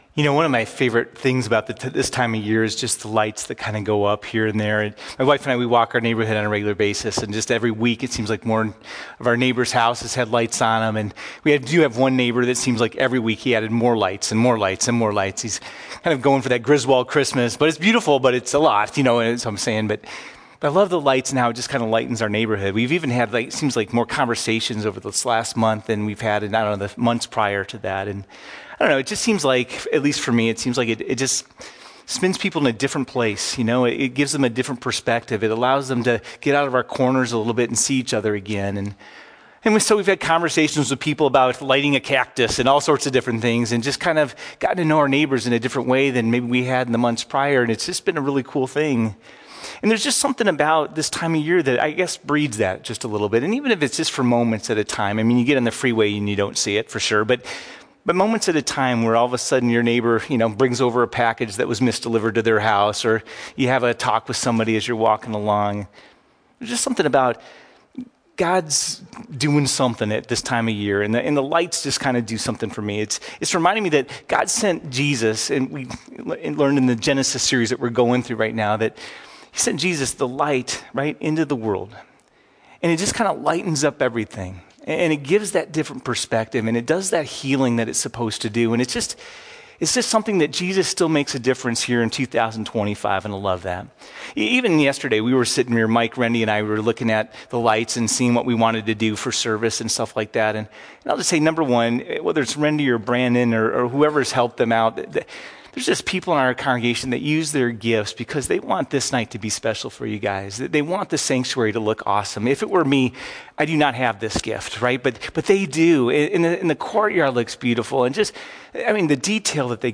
12-24-Sermon.mp3